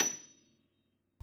53h-pno28-F6.wav